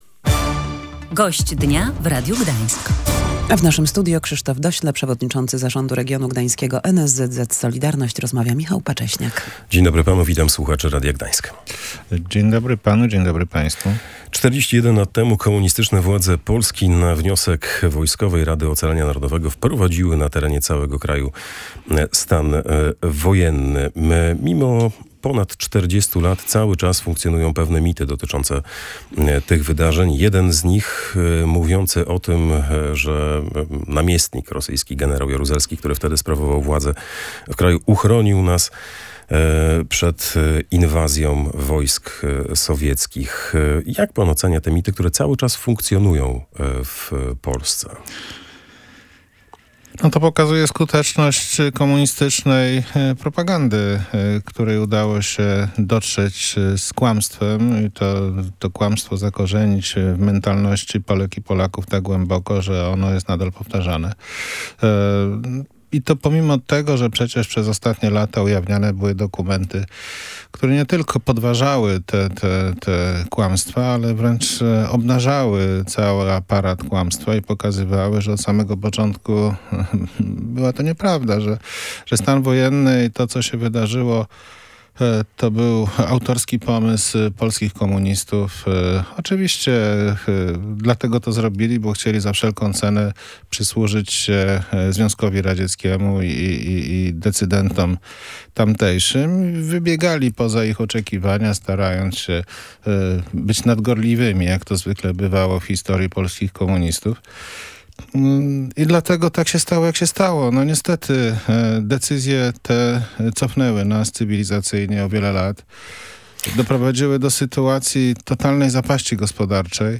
Prowadzący rozmowę dopytywał, dlaczego w świadomości społecznej cały czas istnieje tyle mitów na temat stanu wojennego.